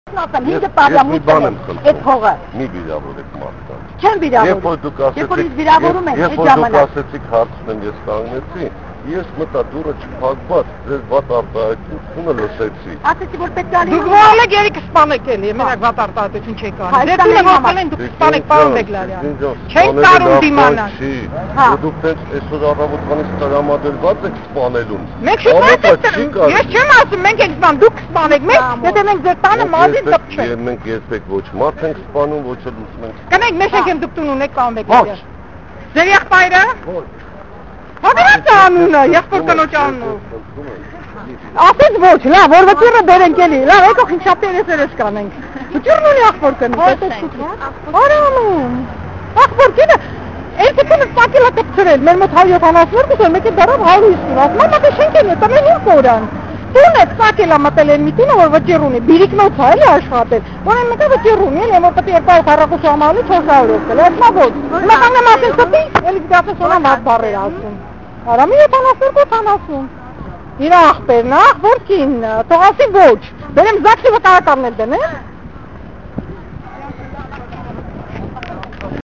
Տրանսպորտի եւ կապի նախարարԳագիկ Բեգլարյանին կառավարության շենքի մոտ ոչ միայն լրագրողներն, այլեւ քաղաքացիներն էին սպասում: Նրան տեսնելով, քաղաքացիները վազեցին հետեւից ու իրար հերթ չտալով՝ սկսեցին իրենց հարցերը տալ: Նախարարն էլ հորդորեց իր հետեւից վիրավորական արտահայտություններ չանել.